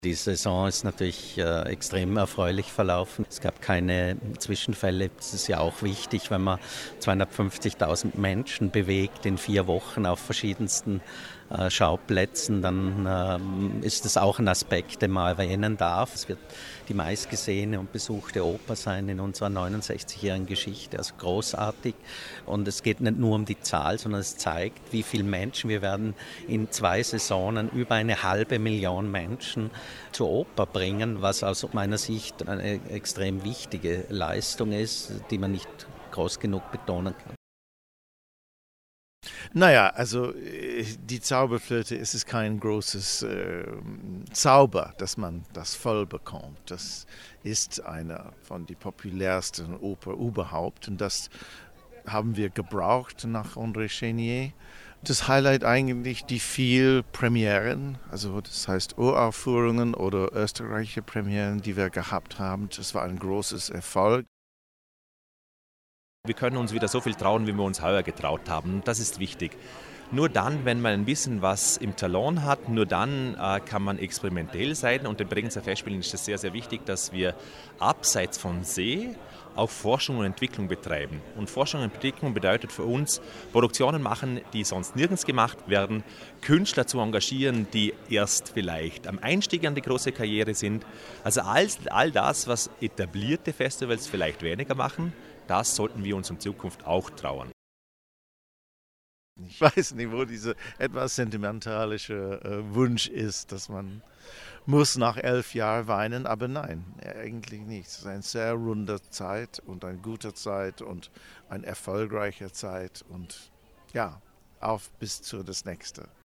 Pressekonferenz Endspurt O-Ton